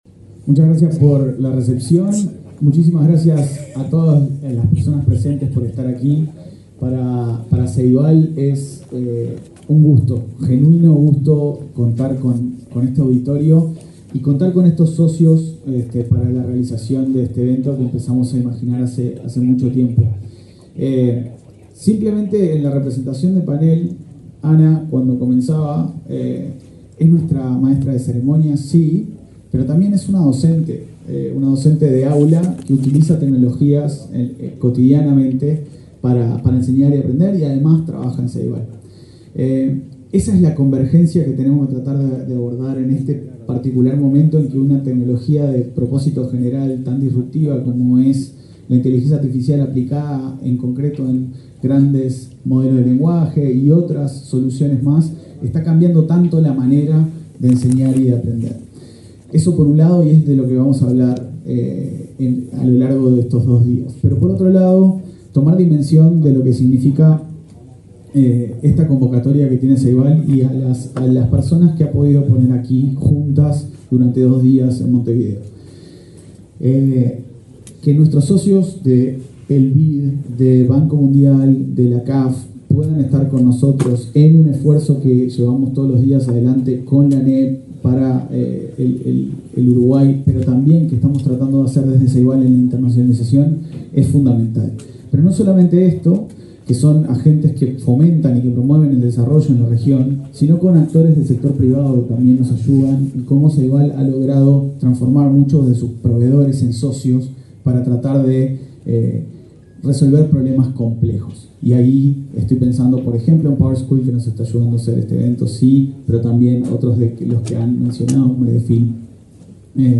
Palabras de autoridades en congreso sobre inteligencia artificial
Palabras de autoridades en congreso sobre inteligencia artificial 08/10/2024 Compartir Facebook X Copiar enlace WhatsApp LinkedIn El presidente del Consejo de Dirección de Ceibal, Leandro Folgar, y la presidenta de la Administración Nacional de Educación Pública, Virginia Cáceres, participaron, este martes 8 en Montevideo, en un congreso internacional sobre inteligencia artificial en la educación.